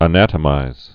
(ə-nătə-mīz)